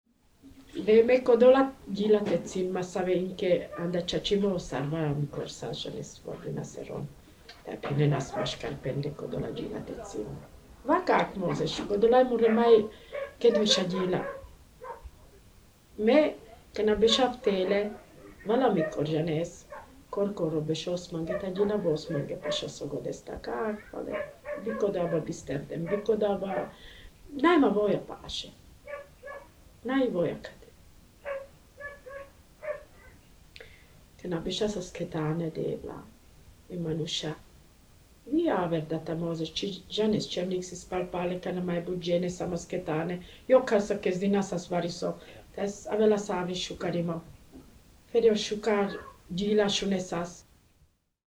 Recording, Interview: